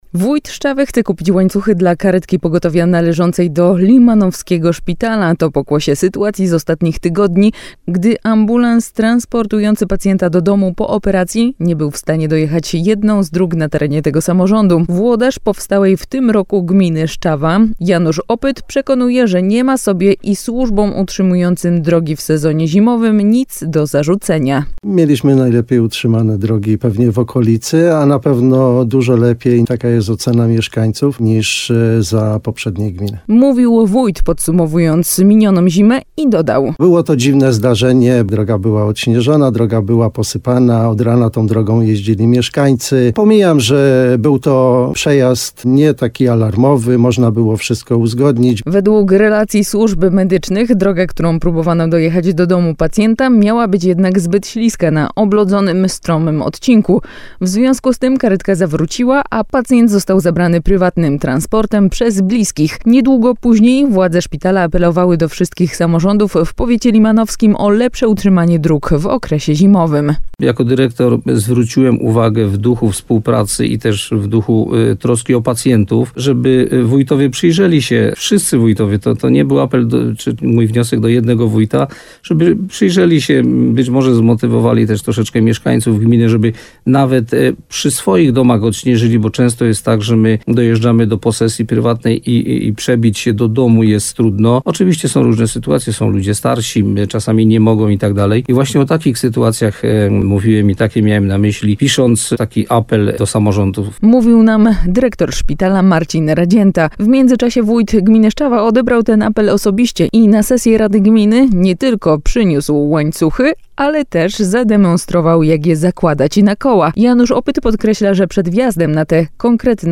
– Mieliśmy najlepiej utrzymane drogi pewnie w okolicy, a na pewno dużo lepiej taka jest ocena mieszkańców niż za poprzedniej gminy – mówił wójt podsumowując minioną zimę, i dodał…